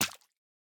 sounds / mob / tadpole / hurt1.ogg
hurt1.ogg